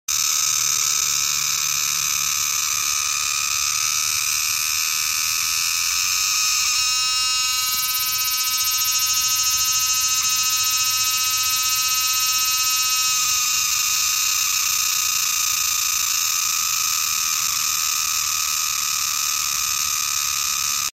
takeootukutuku.mp3